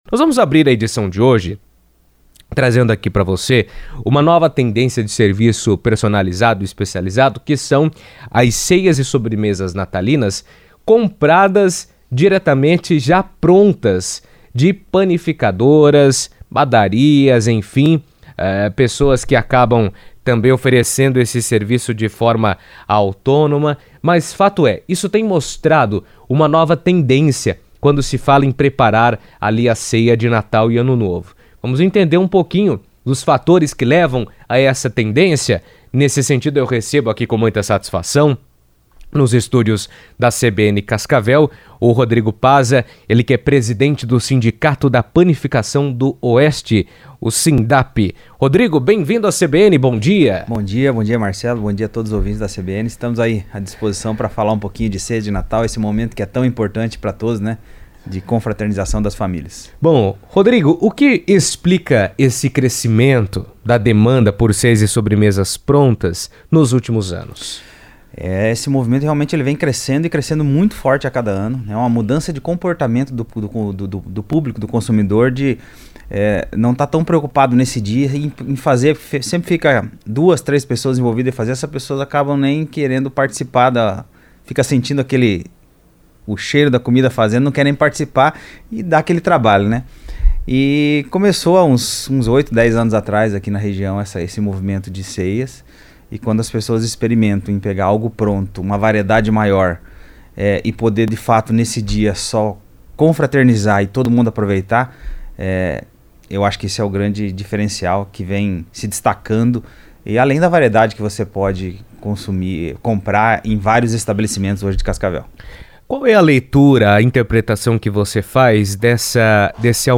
entrevistado na CBN